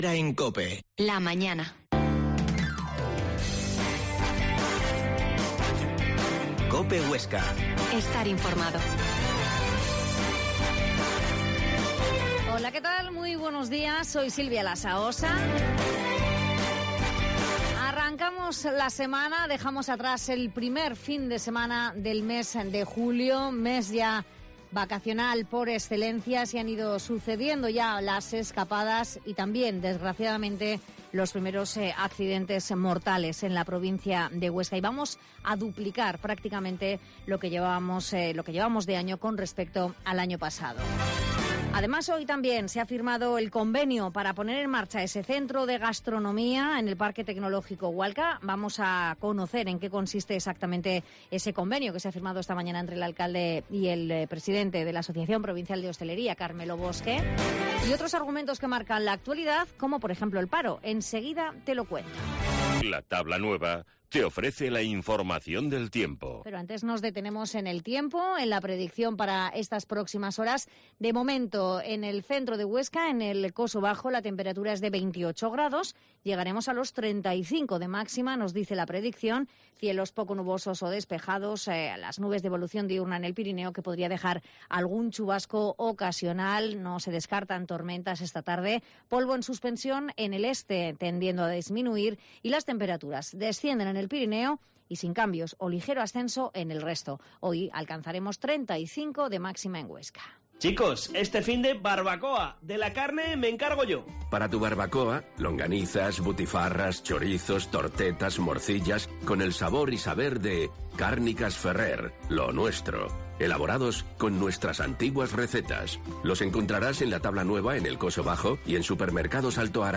Herrera en COPE 12.50h Entrevista a Margarita Padial, Jefa provincial de Tráfico